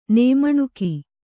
♪ nēmaṇuki